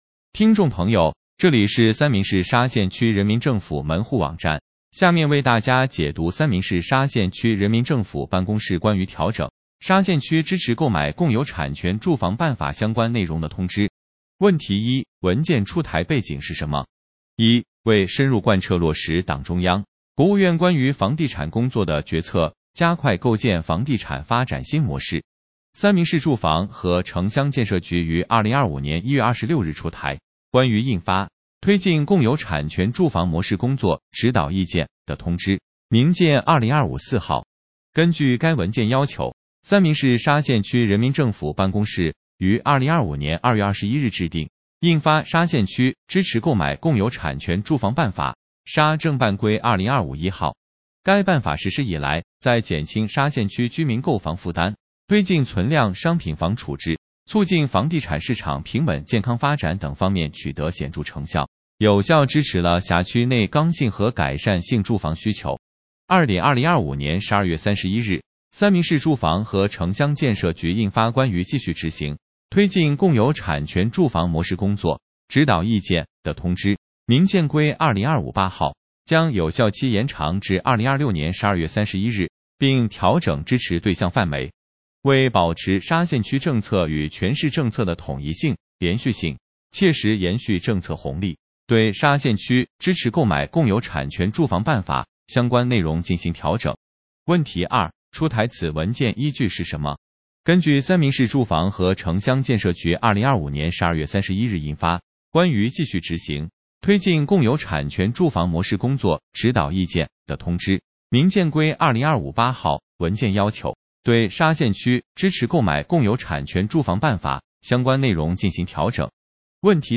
音频解读：三明市沙县区人民政府办公室关于调整《沙县区支持购买共有产权住房办法》相关内容的通知 日期：2026-03-05 来源：沙县区政府办  |  |  |   |   微信  微博  QQ空间 音频解读 您的浏览器不支持音频标签。